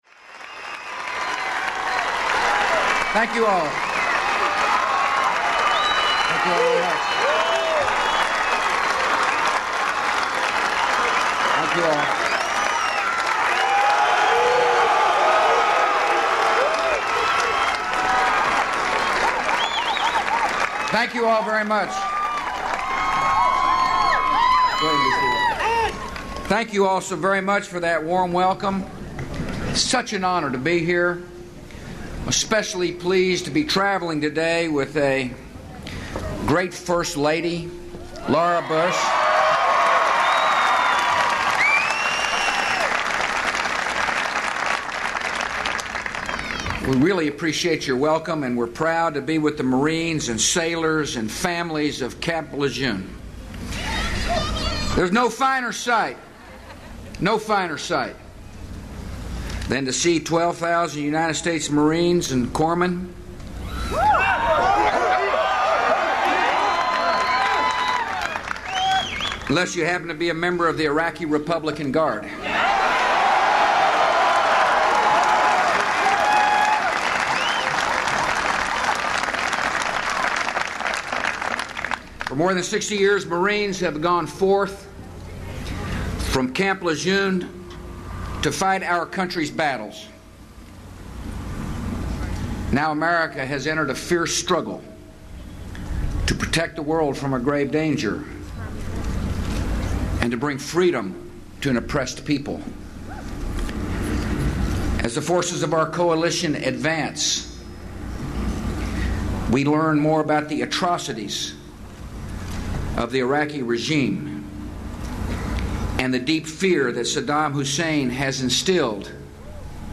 President George W. Bush speaks to an audience of Marines and Marine families at Camp LeJeune, North Carolina. He calls Operation Iraqi Freedom a fierce struggle to protect the world from weapons of mass destruction and the misdeeds of Iraqi war criminals, who have put their own citizens at risk.
Broadcast on CNN, Apr. 3, 2003.